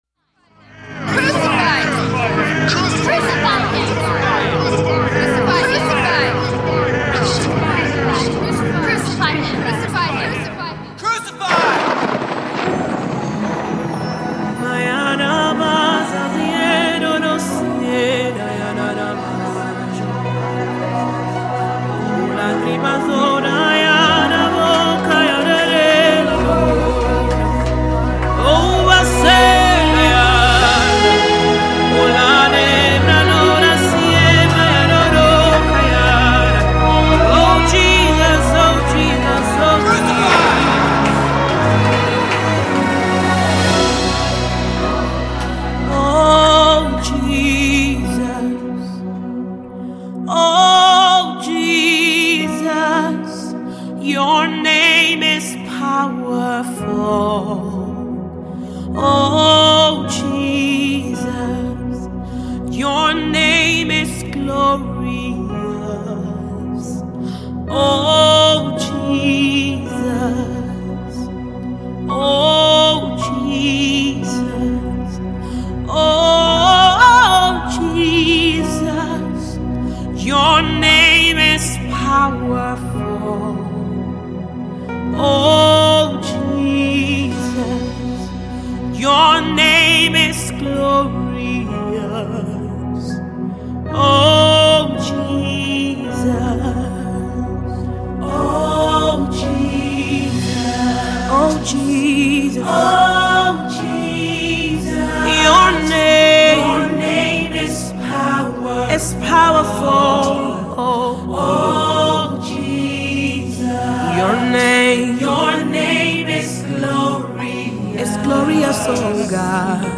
soul-stirring melody
Nigerian gospel singer
serves as an epitome of inspirational worship.